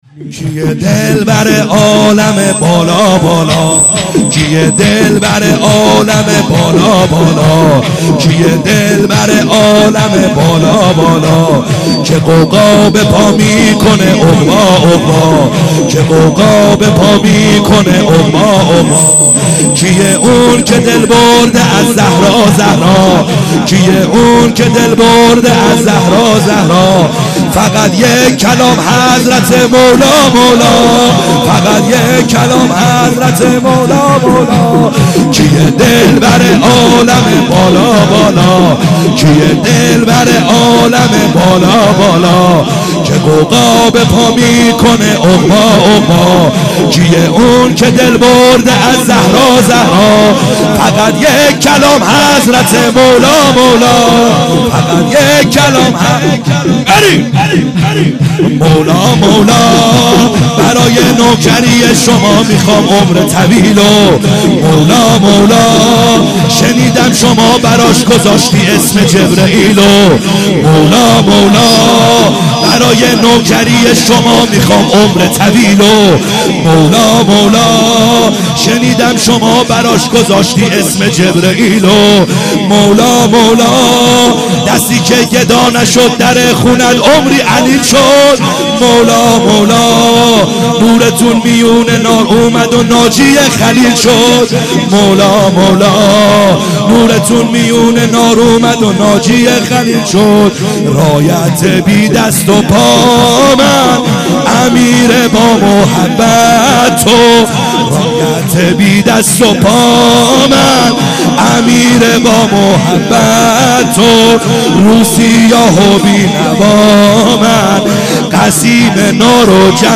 خیمه گاه - بیرق معظم محبین حضرت صاحب الزمان(عج) - سرود | کیه دلبر عالم